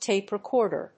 アクセントtápe recòrder